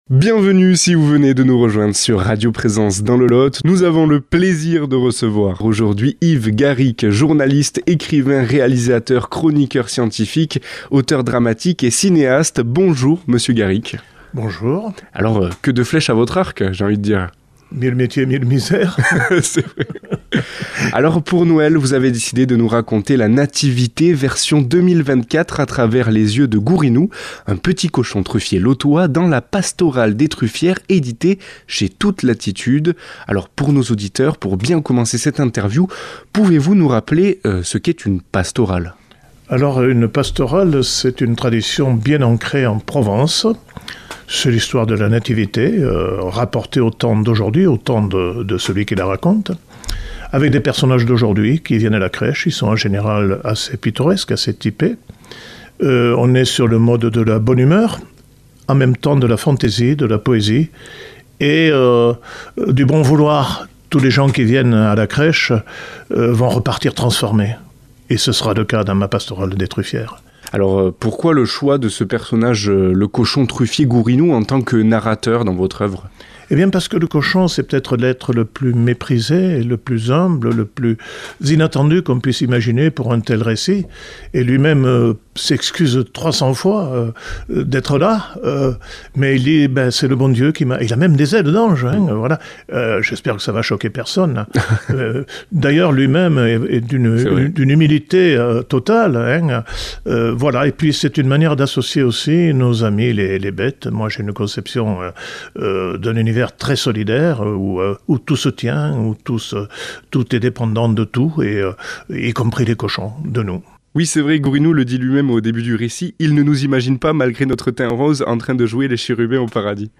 a comme invité au studio